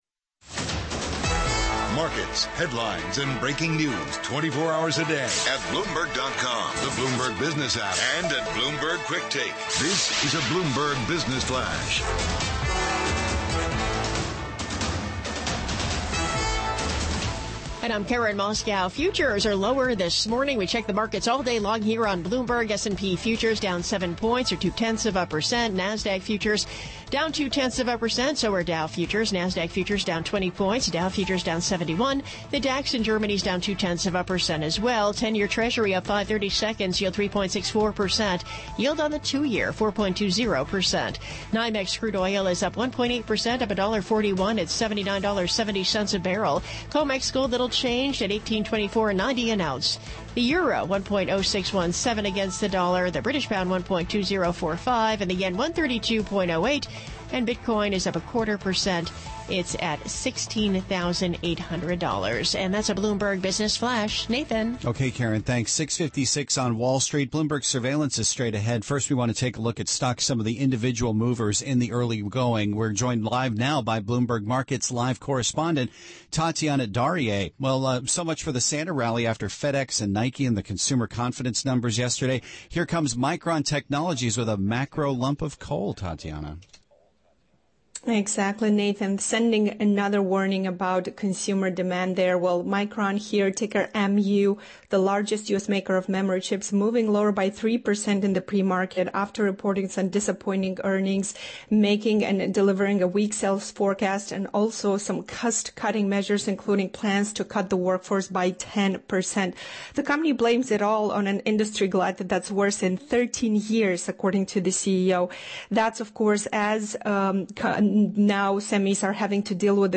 Bloomberg Daybreak: December 22, 2022 - Hour 2 (Radio)